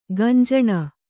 ♪ gañjaṇa